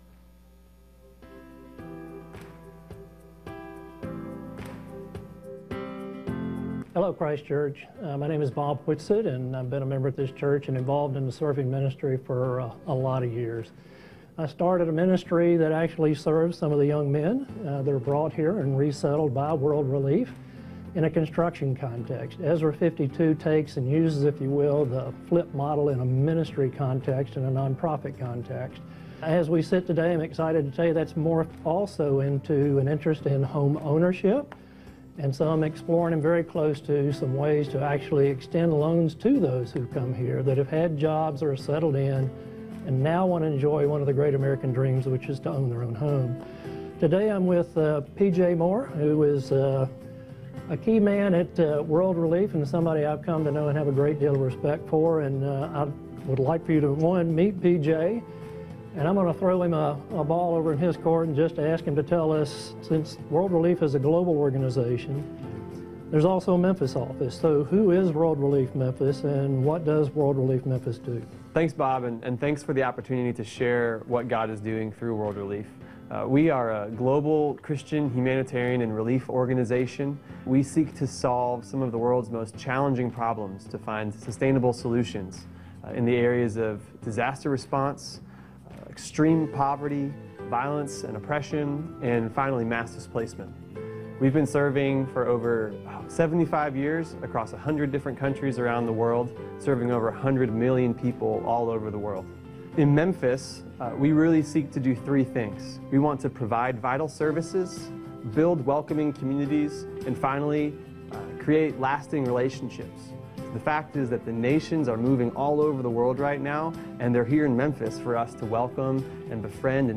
A message from the series "Colossians." The final week of our Colossians series.